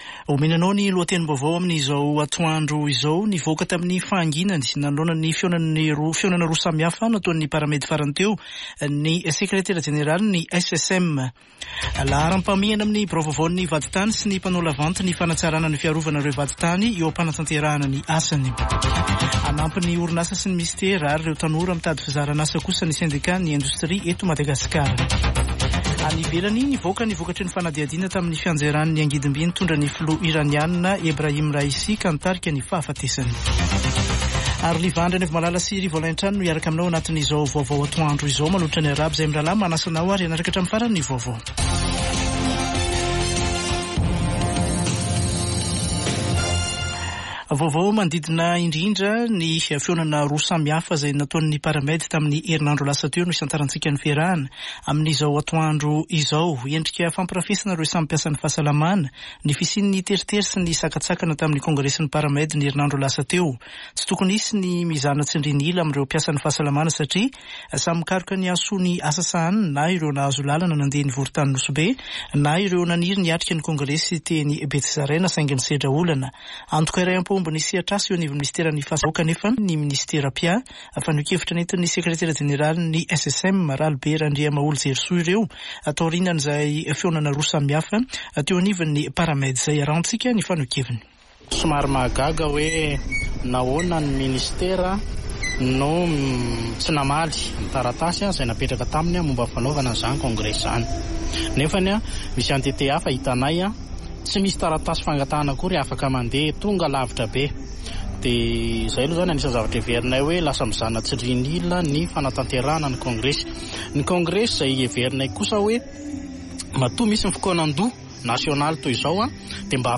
[Vaovao antoandro] Alatsinainy 2 septambra 2024